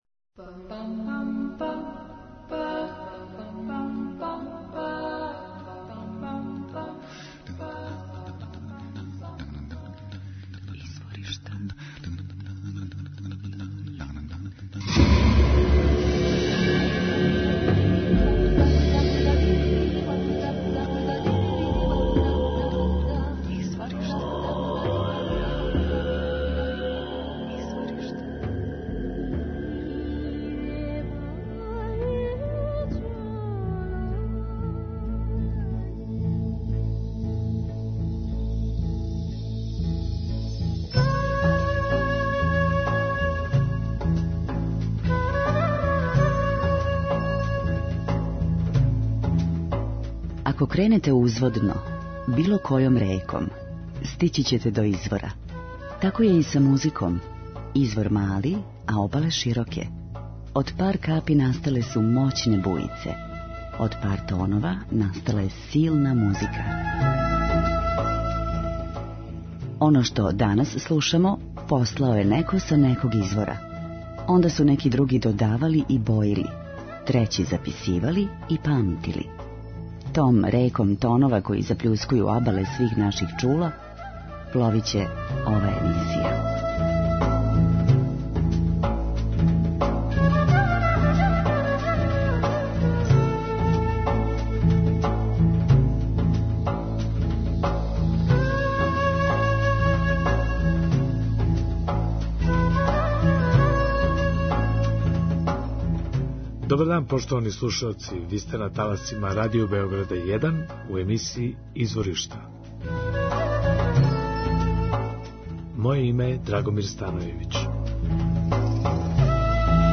Canzoniere Grecanico Salentino - фасцинантна италијанска дихотомија, традиције и модерности спаја се у музици овог састава.
У две речи: World Music.